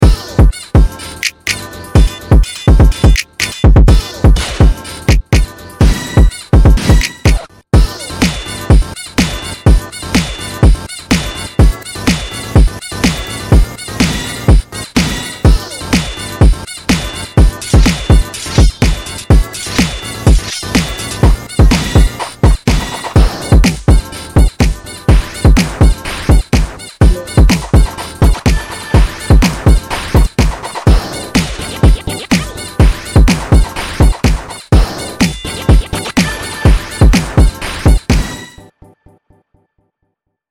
couldnt stop attempting to make jersey at some point